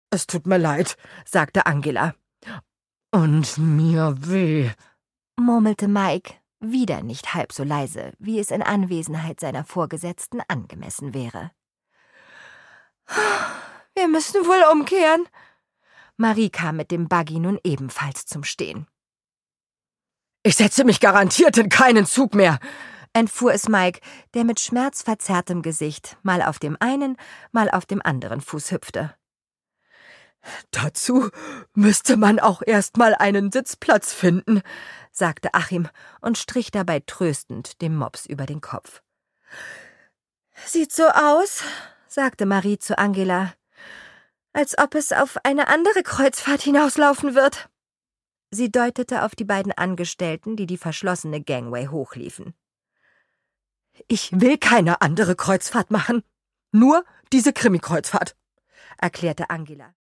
Produkttyp: Hörbuch-Download
Gelesen von: Nana Spier
Denn ihre vielseitige Interpretationskunst und ihr Gespür für Witz und Timing kommen in dieser Reihe voll zum Tragen.